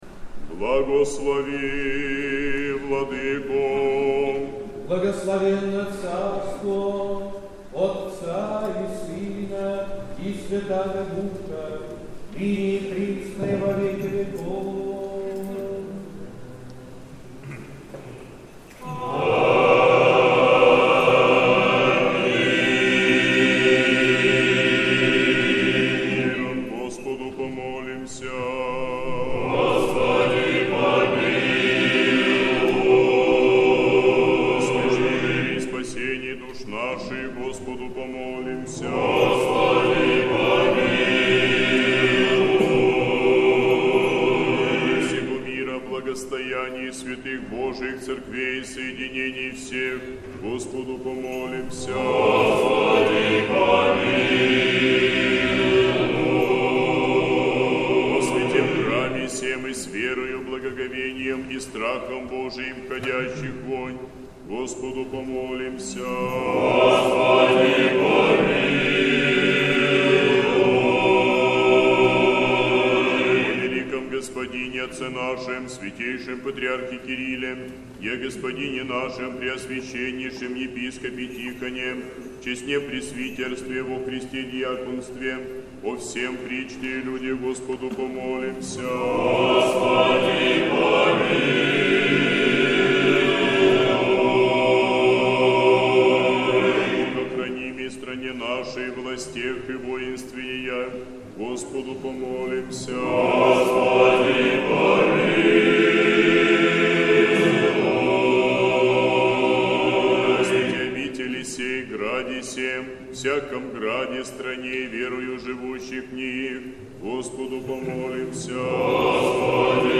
Сретенский монастырь. Божественная литургия. Хор Сретенского монастыря.
Божественная литургия в Сретенском монастыре в Неделю 12-ю по Пятидесятнице